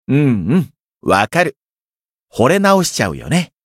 觉醒语音 嗯嗯，我懂。